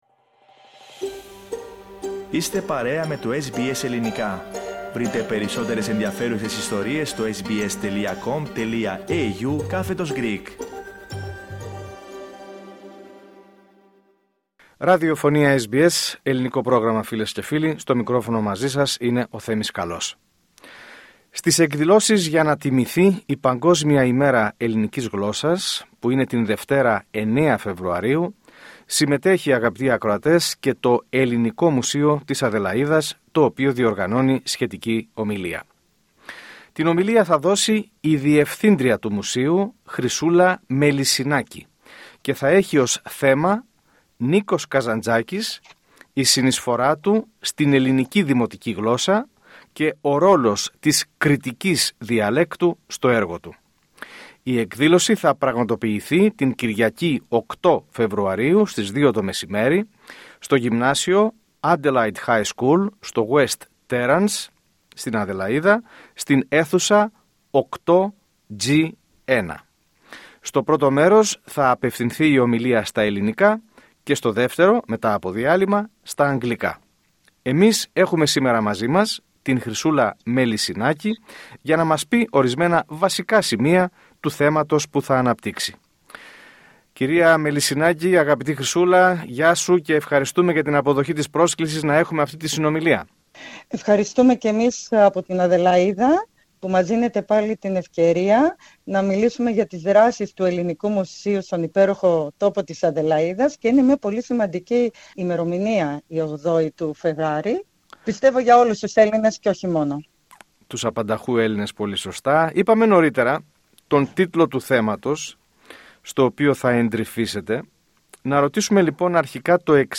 Σε συνέντευξη